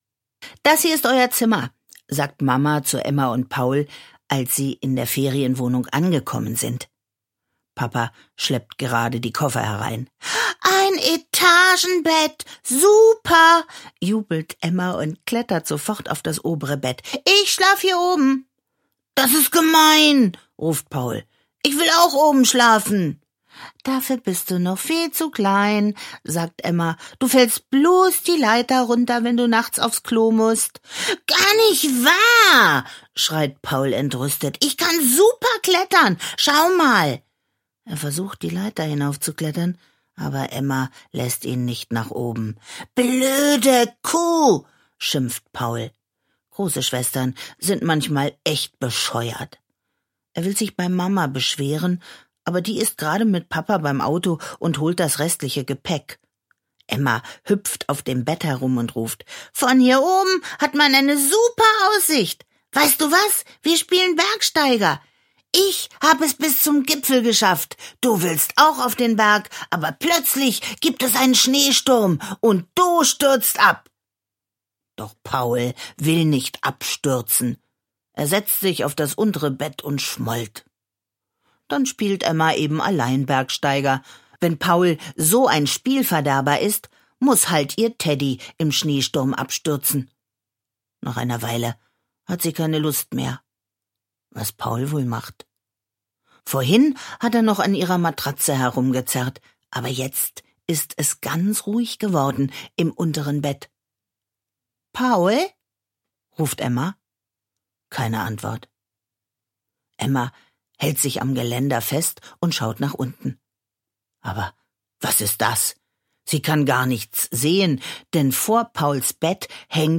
Gutenacht-Geschichten
Schlagworte Einschlafen • Gutenacht-Geschichten • Gute-Nacht-Geschichten • Hörbuch; Lesung für Kinder/Jugendliche • Kindergeschichten • Kinder/Jugendliche: Anthologien